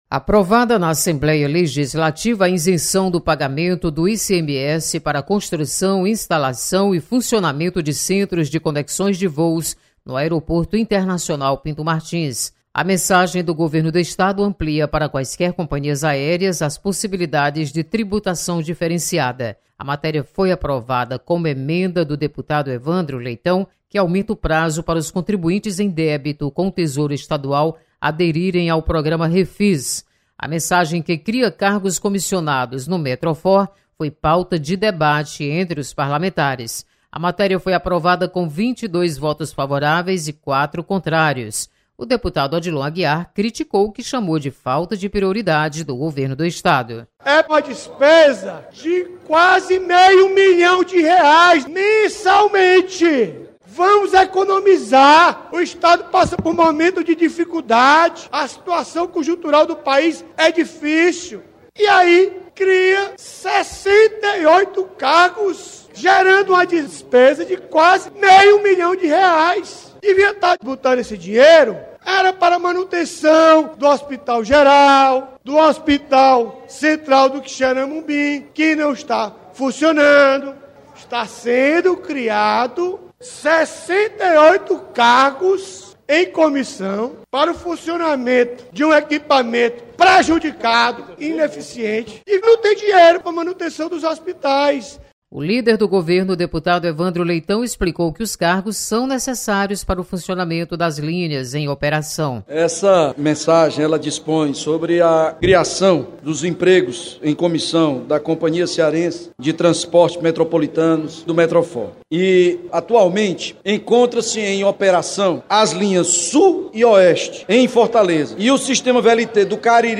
Assembleia aprova isenção de ICMS para o Hub. Repórter